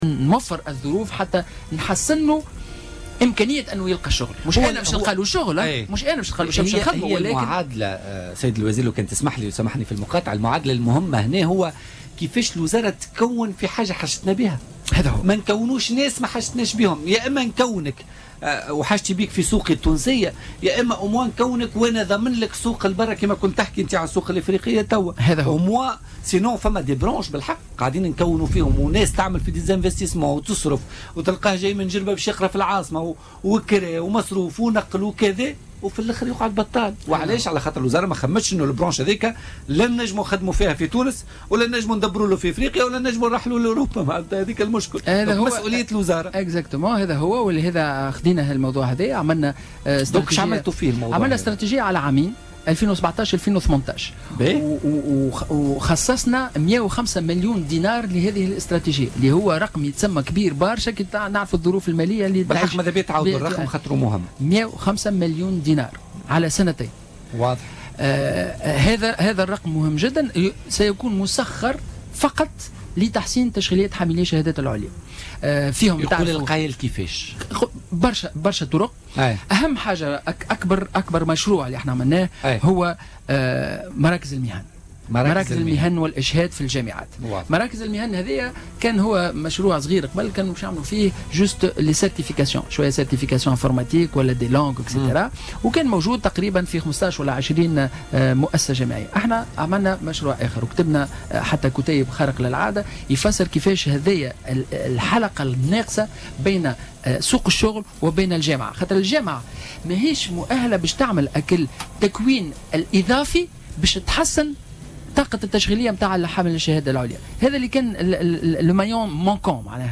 وأضاف في مداخلة له اليوم في برنامج "بوليتيكا" أن هذا الإجراء يندرج في اطار استراتيجية تمتد على سنيتن (2017-2018) لتحسين إمكانية البحث عن شغل من خلال إحداث مراكز المهن والاشهاد في الجامعات، الهدف منها تقديم تكوين إضافي للطلبة، وفق تعبيره.